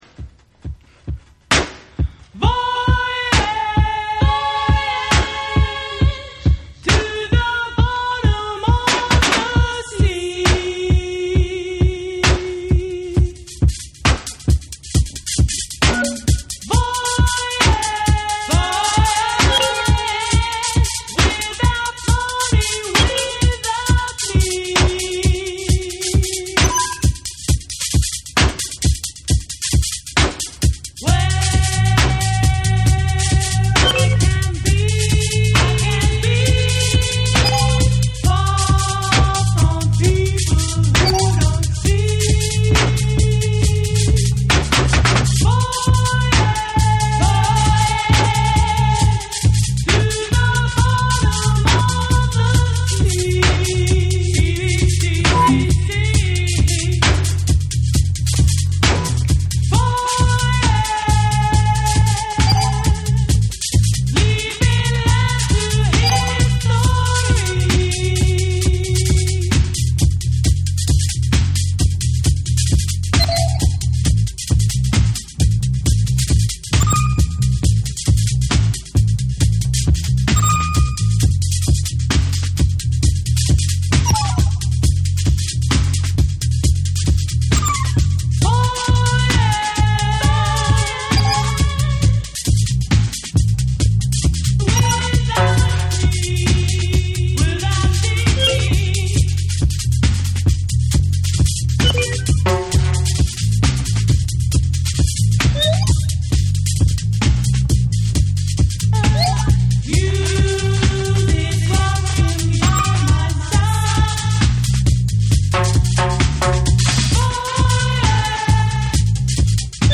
REGGAE & DUB / NEW WAVE & ROCK